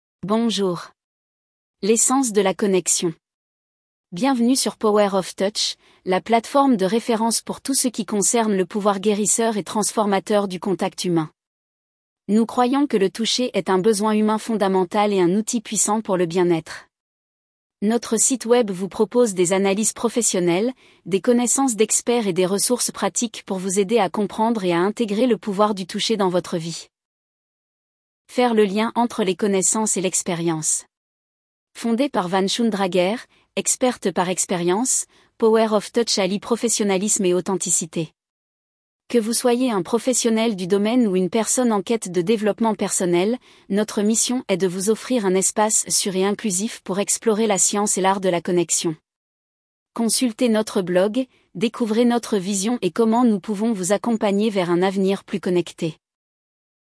mp3-text-to-voice-pouvoir-du-toucher-bonjour-power-of-touch.mp3